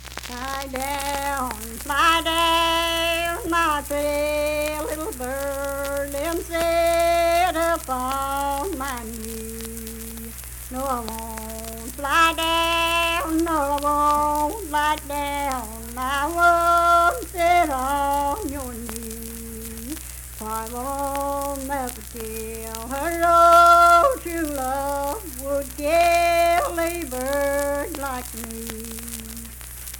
Unaccompanied vocal music performance
Verse-refrain, 2(2-4).
Voice (sung)